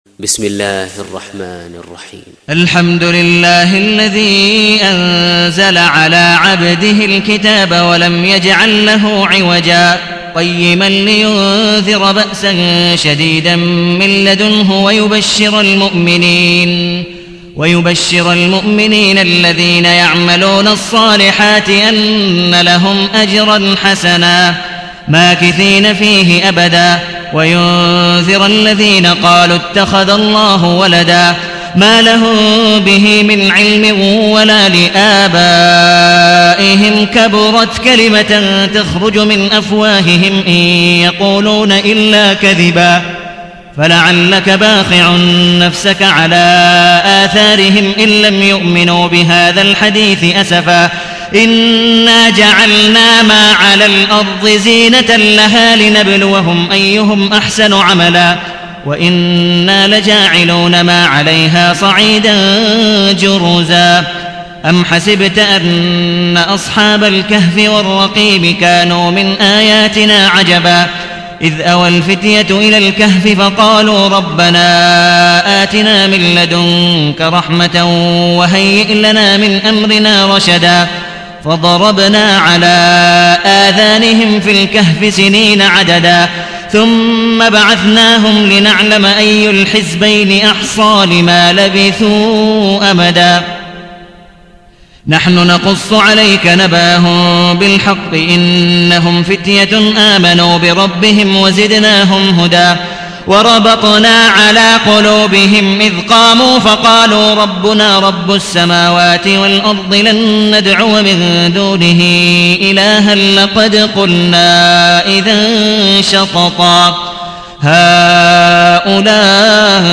تحميل : 18. سورة الكهف / القارئ عبد الودود مقبول حنيف / القرآن الكريم / موقع يا حسين